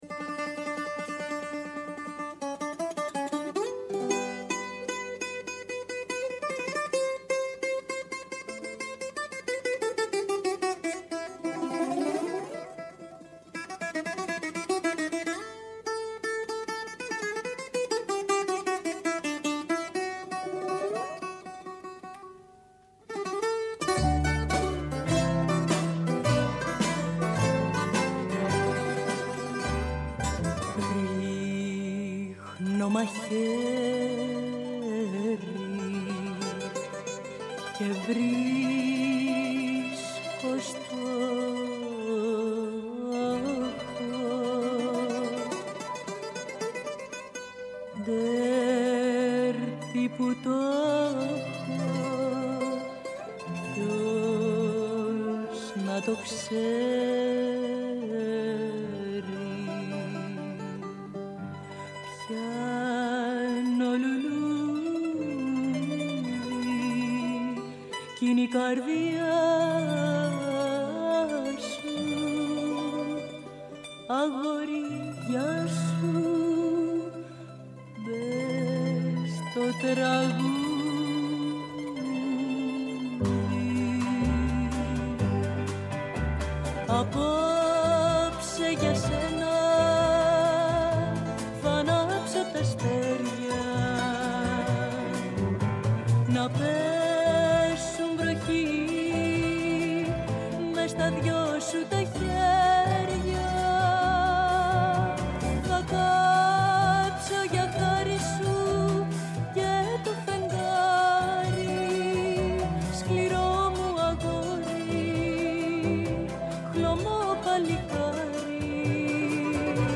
Ενστάσεις, αναλύσεις, παρατηρήσεις, αποκαλύψεις, ευχές και κατάρες, τα πάντα γίνονται δεκτά. Όλα όσα έχουμε να σας πούμε στο Πρώτο Πρόγραμμα της Ελληνικής Ραδιοφωνίας, Δευτέρα έως και Πέμπτη, 1 με 2 το μεσημέρι.